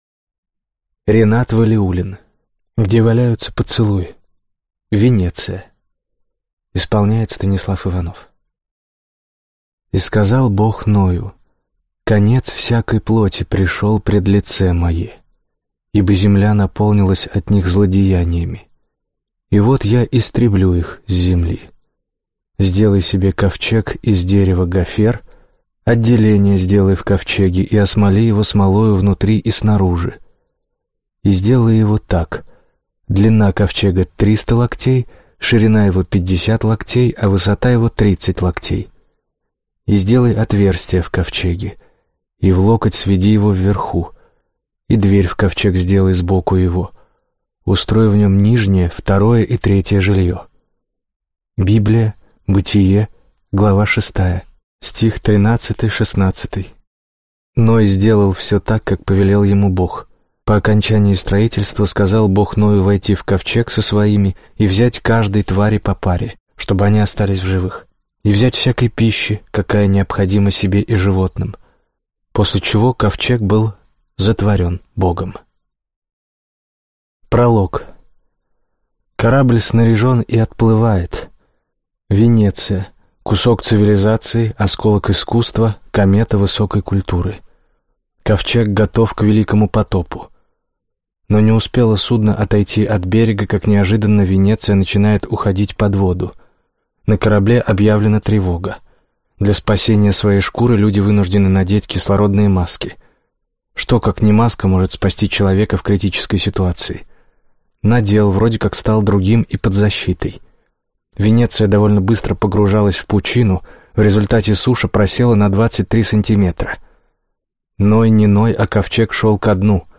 Аудиокнига Где валяются поцелуи. Венеция - купить, скачать и слушать онлайн | КнигоПоиск